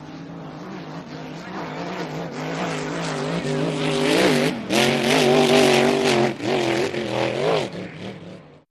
Boat Race Single Boat Race By